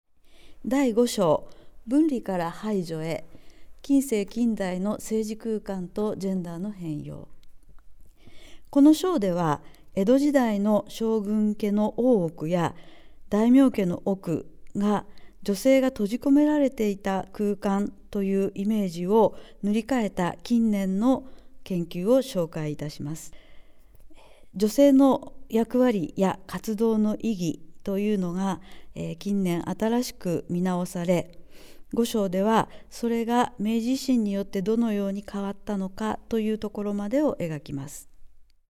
音声ガイドをお手元でも体験できます